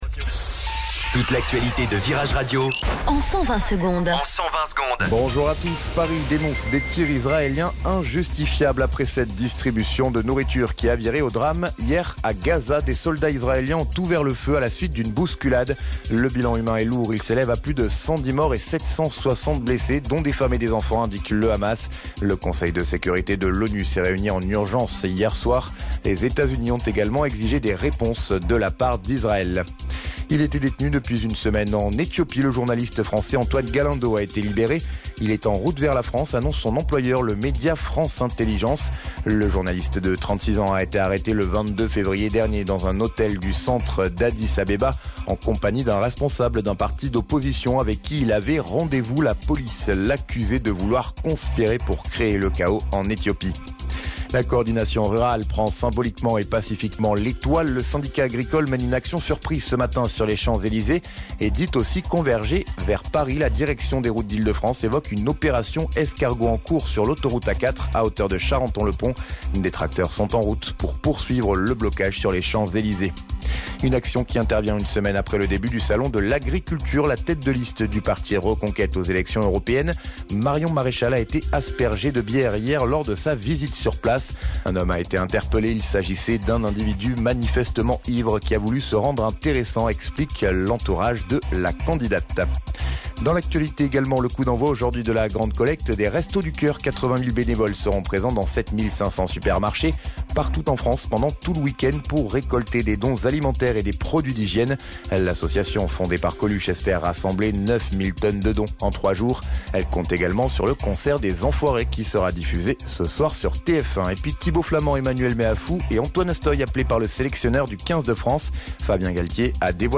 Flash Info Grenoble 01 Mars 2024 Du 01/03/2024 à 07h10 Flash Info Télécharger le podcast Partager : À découvrir Oasis à la rescousse de New Order ?